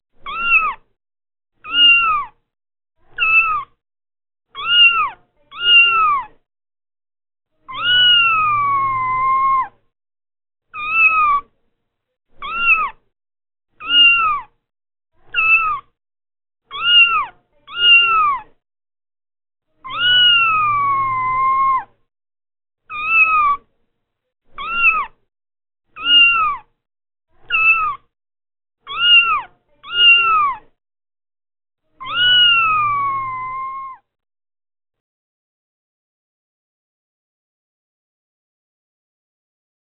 صوت قطة – تغاريد البادية
الالات واصوات